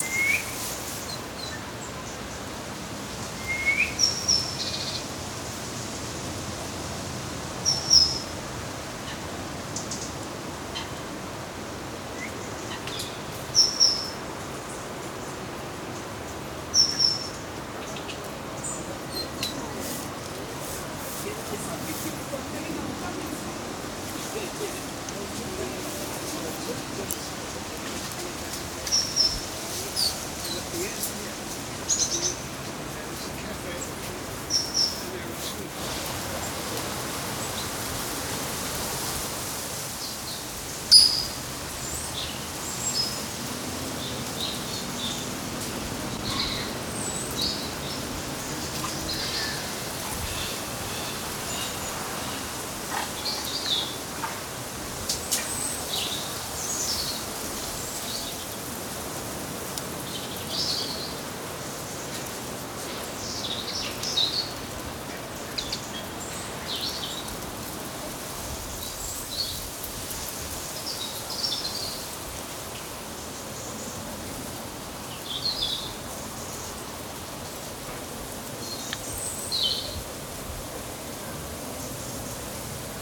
На этой странице собраны звуки городского шума: гул машин, разговоры прохожих, сигналы светофоров и другие атмосферные звуки улиц.
Шум городской жизни